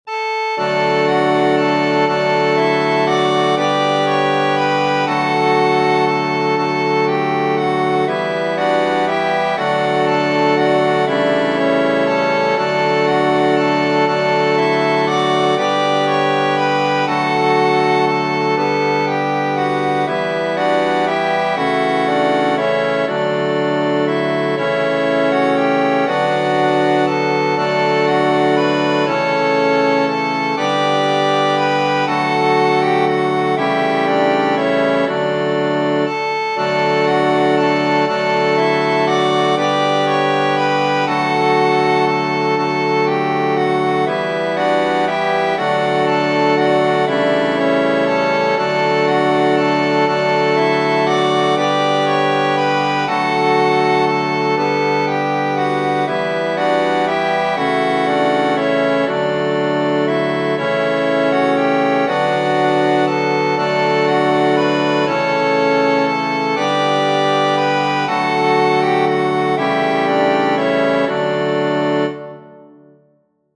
Genere: Religiose